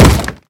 Minecraft Version Minecraft Version snapshot Latest Release | Latest Snapshot snapshot / assets / minecraft / sounds / mob / zombie / wood2.ogg Compare With Compare With Latest Release | Latest Snapshot
wood2.ogg